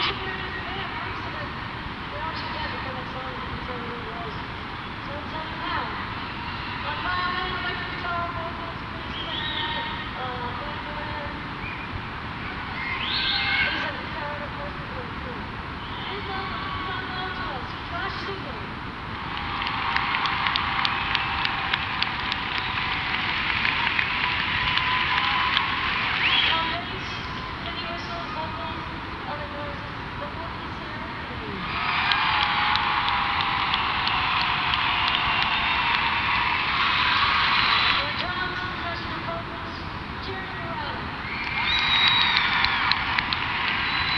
lifeblood: bootlegs: 1997-06-03: madison square garden - new york, new york (alternate recording)
05. band introductions (0:44)